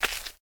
SBobWalk.ogg